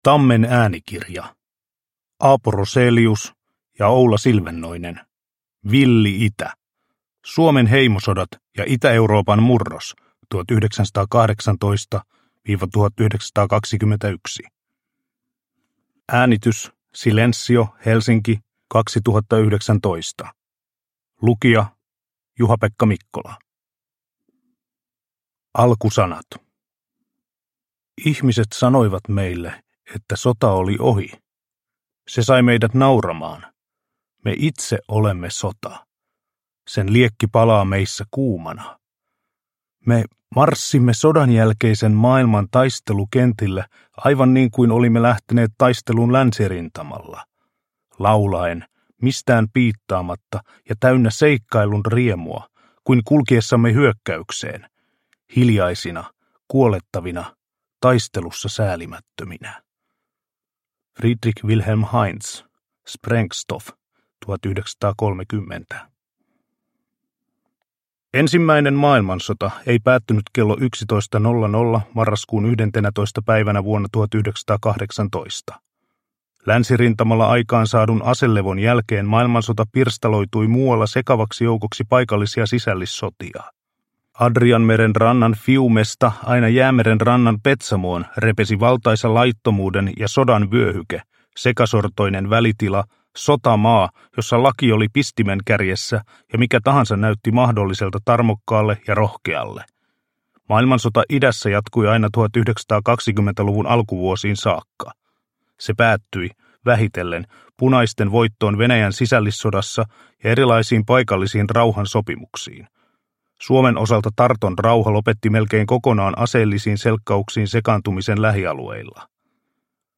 Villi itä – Ljudbok – Laddas ner